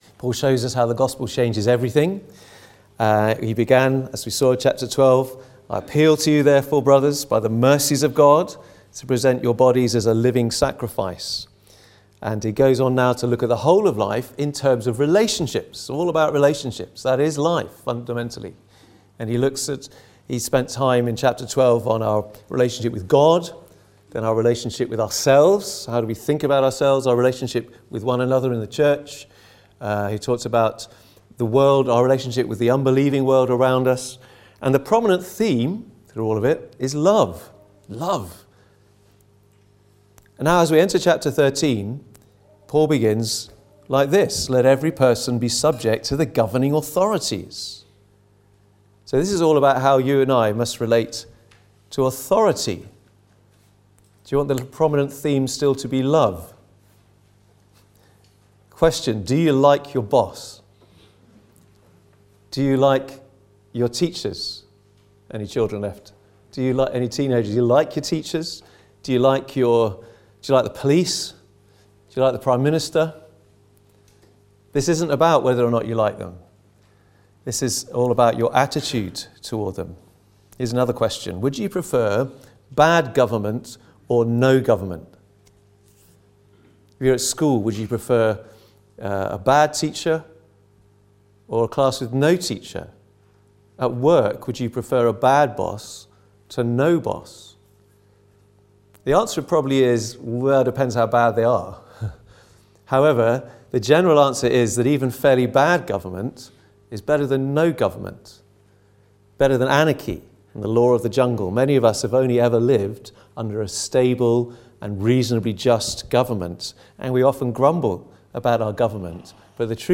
Book of Romans Passage: Romans 13:1-7 Service Type: Sunday Morning « Are You Listening to God?